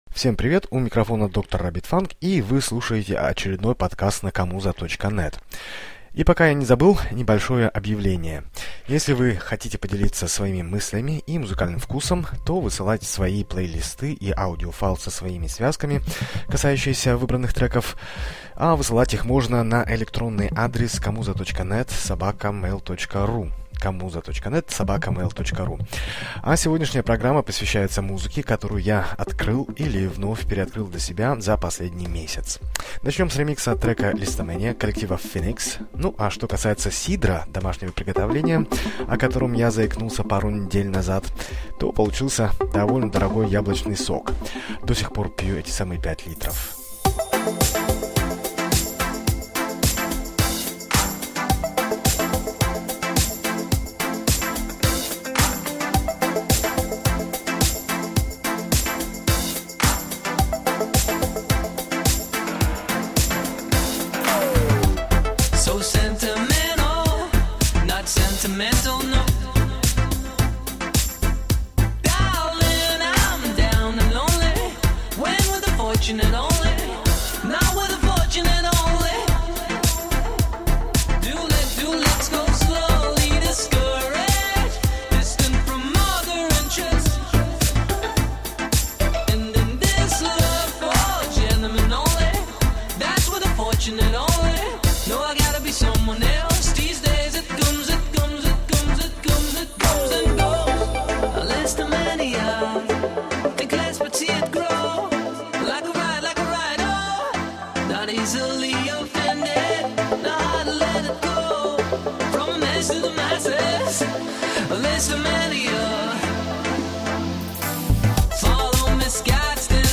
музыка для вечеринки
музыкальный подкаст
электронно-танцевальные треки